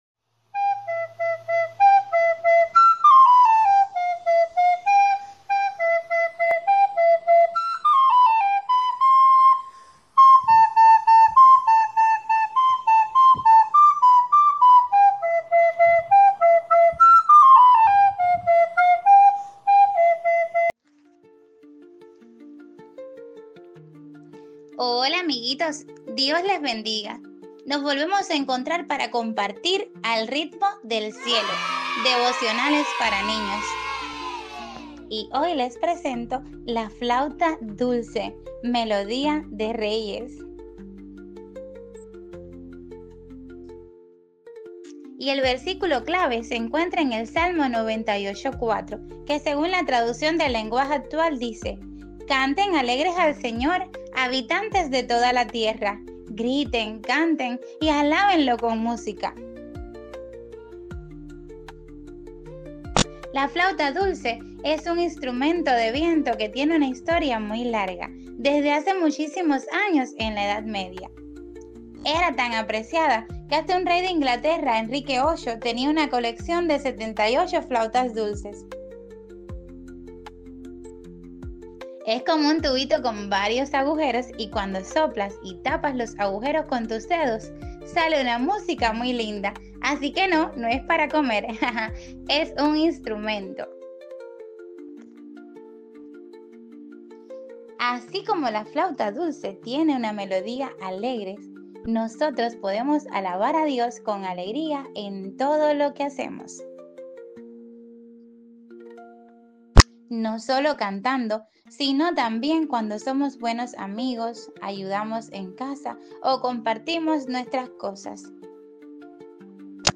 ¡Al Ritmo del Cielo! – Devocionales para Niños